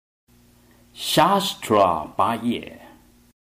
唱誦